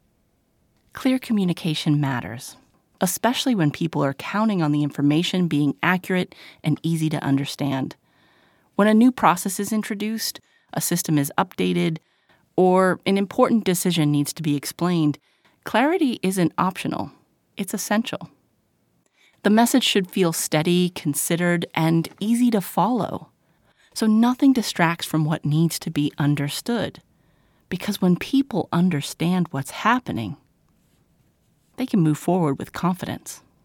Steady, professional narration for corporate communication
~ Clear, natural delivery recorded with broadcast-quality sound, so your message is easy to follow and your project stays on track ~
Corporate Narration Sample
Recorded in my studio.